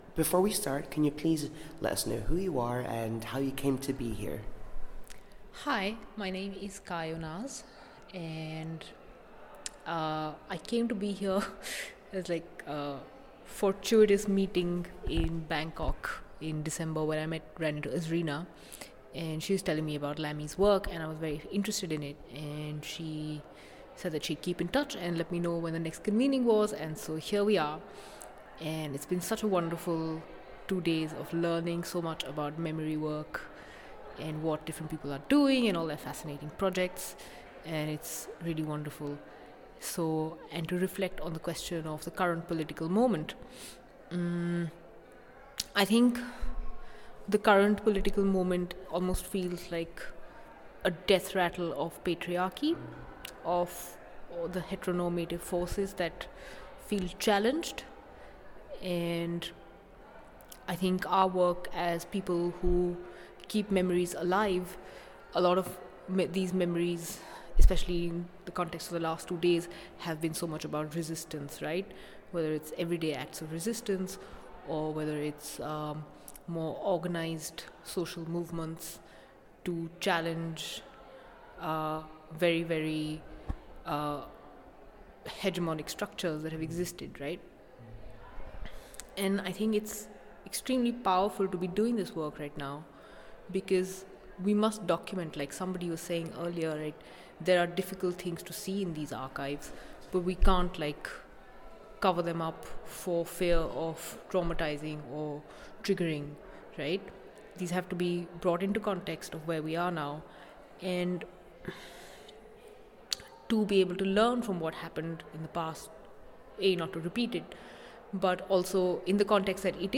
The following audio reflections, recorded during the Brighton convening, feature members of the UK Community of Practice addressing the urgent question: How can we use the current political climate we’re in right now to shape the needs of memory work?